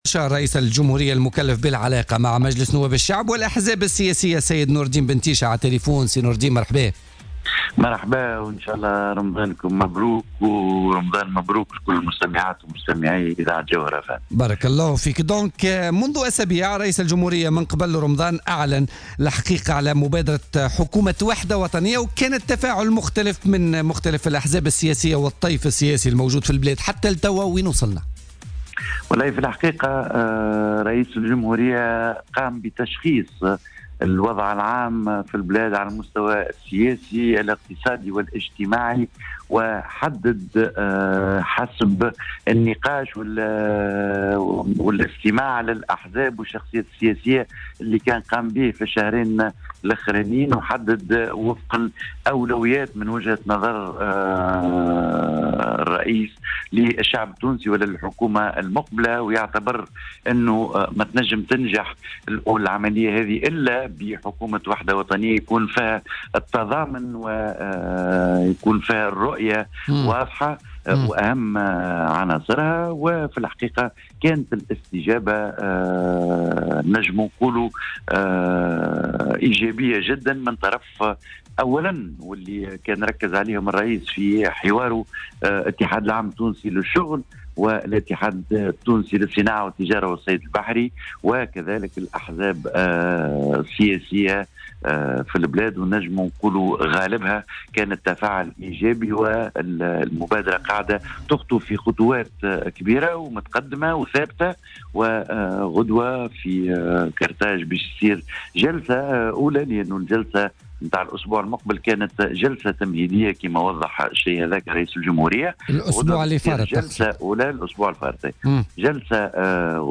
وأضاف في مداخلة له اليوم في برنامج "الحدث" أنه من المنتظر إن يلتقي رئيس الجمهورية الباجي قائد السبسي غدا الأربعاء بممثلين عن أحزاب نداء تونس و النهضة و آفاق تونس والاتحاد الوطني الحر و المبادرة و حركة الشعب و المسار وحركة المشروع إضافة إلى اتحاد الشغل ومنظمة الأعراف.